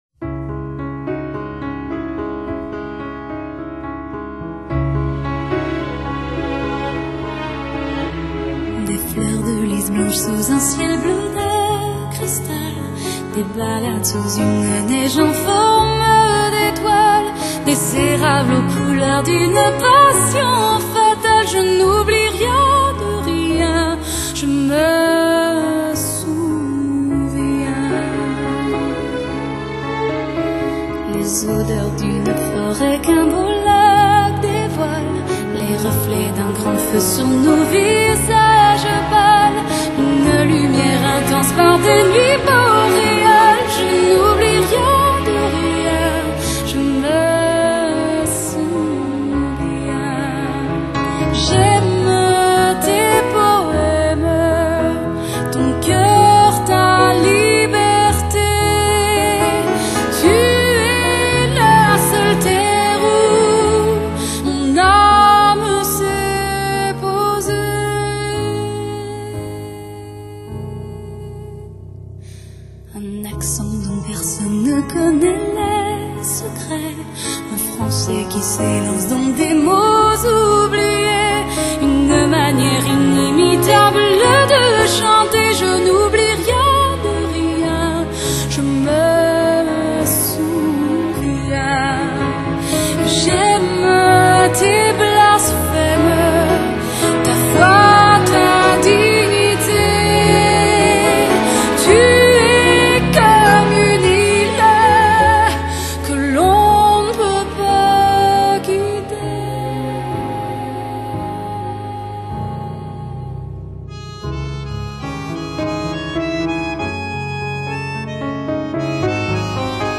그녀의 음색은 신비롭고 묘한 분위기가 ...